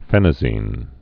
(fĕnə-zēn) also phen·a·zin (-zĭn)